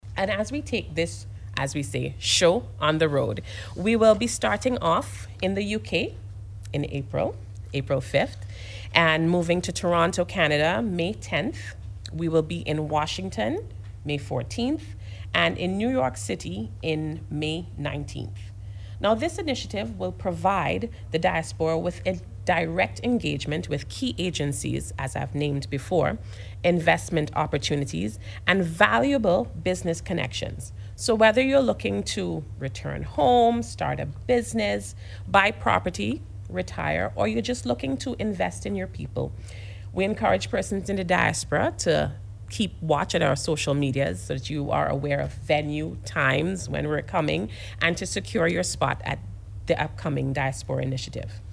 spoke about this new initiative at a recent Media Conference at Holiday Inn Express and Suites at Diamond.